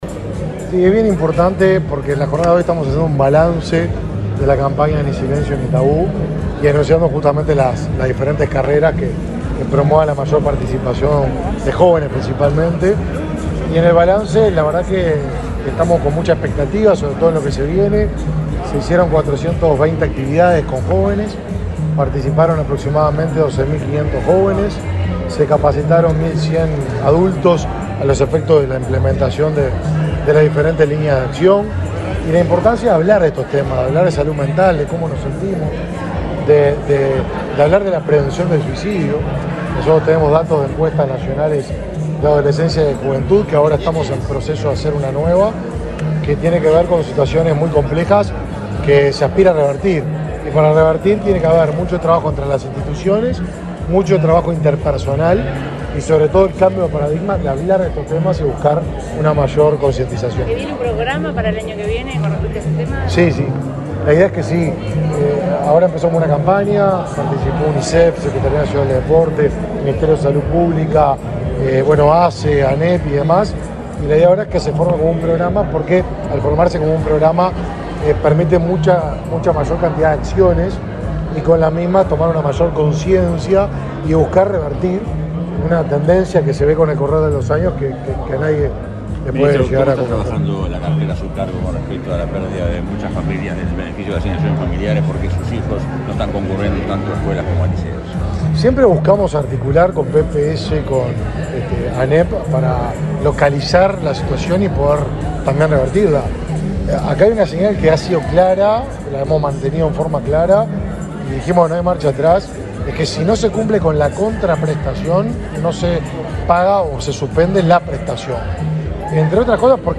Declaraciones del ministro Martín Lema
El ministro de Desarrollo Social, Martín Lema, dialogó con la prensa luego de participar del acto de balance de la campaña Ni Silencio Ni Tabú y el